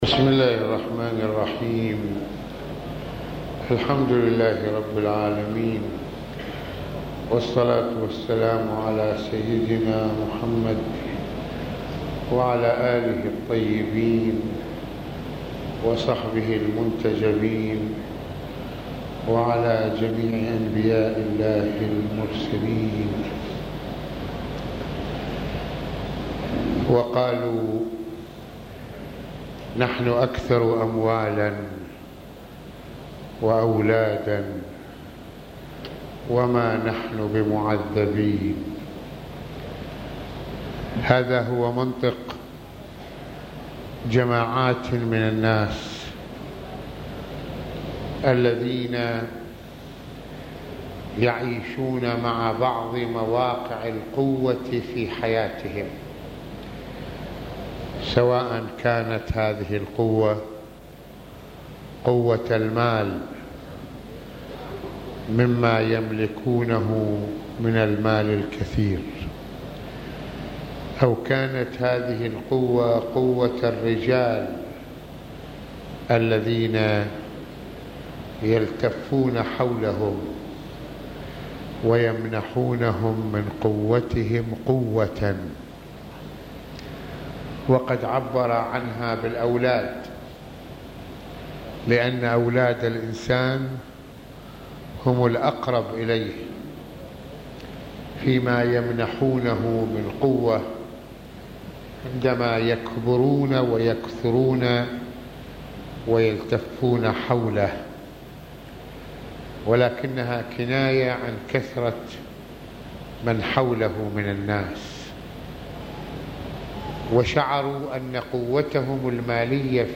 - يتحدّث العلامة المرجع السيّد محمّد حسين فضل الله(رض) في هذه المحاضرة عن تفاعل بعض الناس مع مواقع قوة المال والأولاد بالمعنى السلبي، إذ يعتبرون ذلك فضلاً مختصاً من الله بهم فالله لن يعذبهم على أفعالهم لأن الله لا يعذّب الناس الذين اختصهم بفضله..